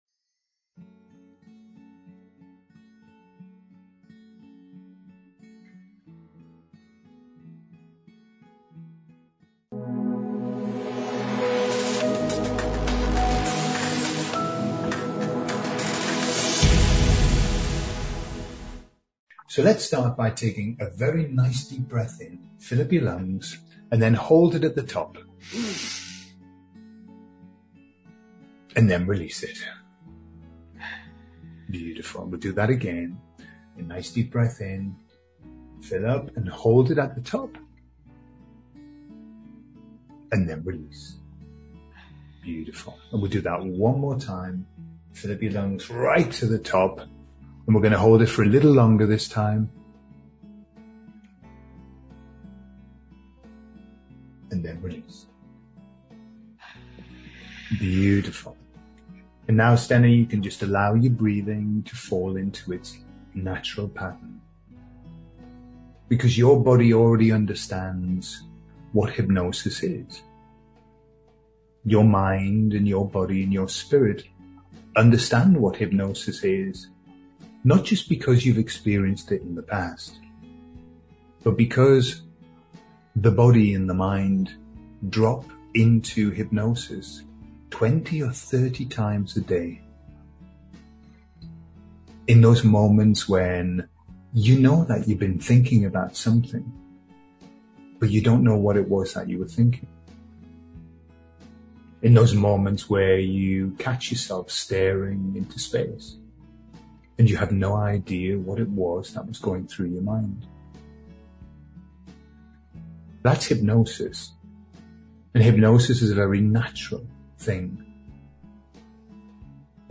LIVE Hypnotic Induction explained
You might be surprised just how many subtle language patterns are present in what appears to be a gentle chat. Join us to learn some of the secrets of the hypnotic induction.